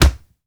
pgs/Assets/Audio/Punches/punch_general_body_impact_03.wav at master
punch_general_body_impact_03.wav